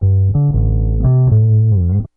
BASS 1.wav